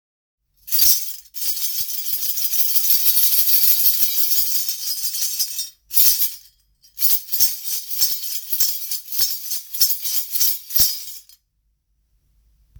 >ベル
王冠を潰したタンバリンのような形。シャラシャラと心地よい響きです。
素材： 鉄 木